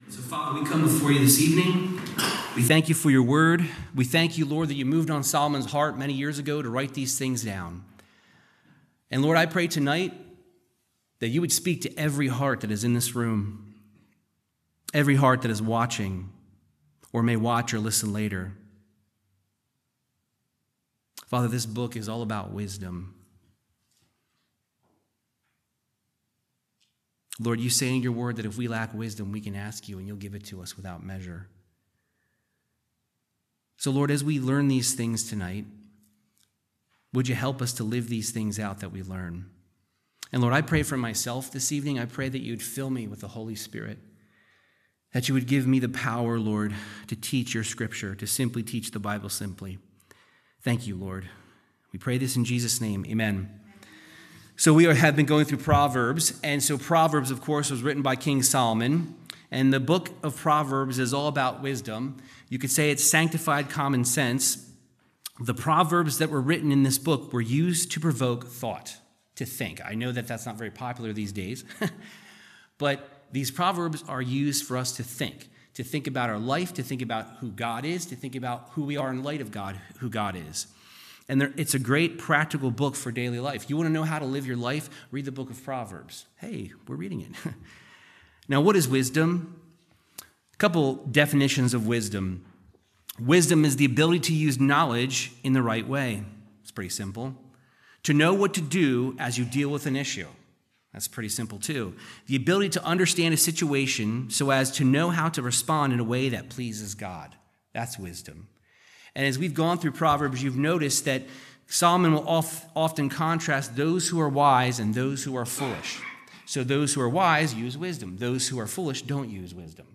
Verse by verse Bible teaching through the book of Proverbs. Exploring the wisdom of King Solomon in Proverbs 21-22:5